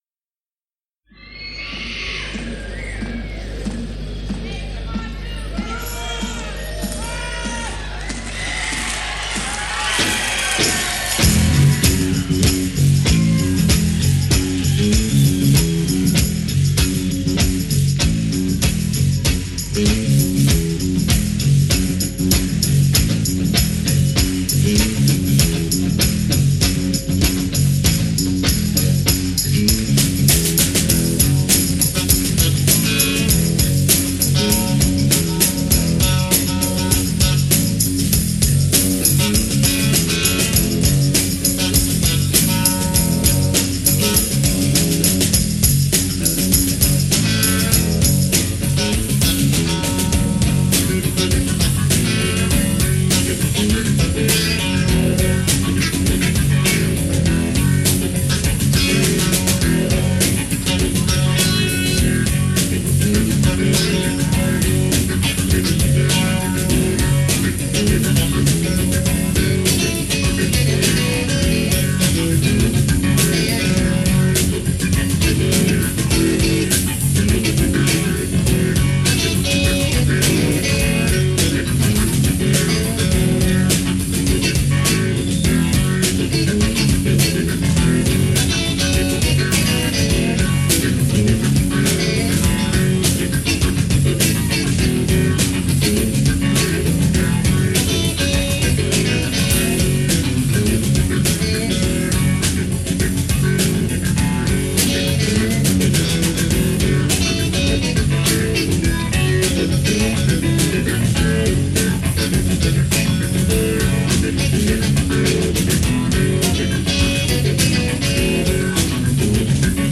live in Paris